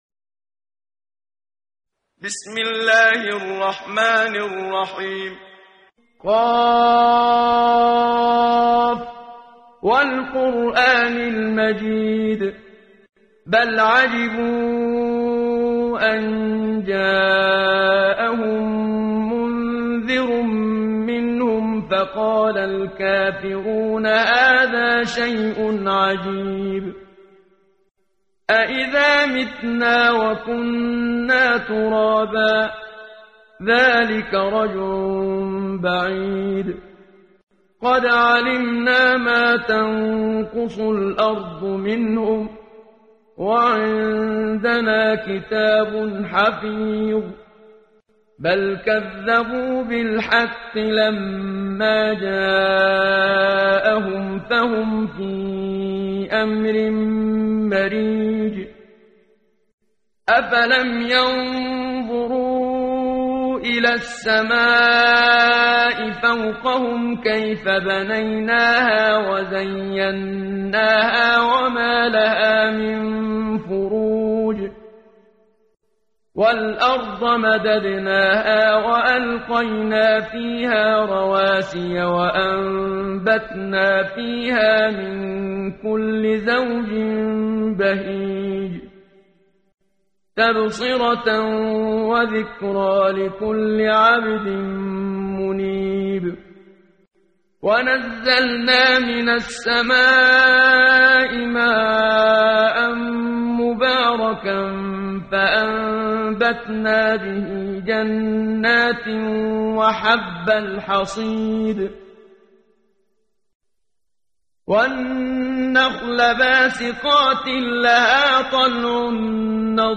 قرائت قرآن کریم ، صفحه 518 ، سوره مبارکه « ق» آیه 1 تا 15 با صدای استاد صدیق منشاوی.